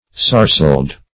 \Sar"celed\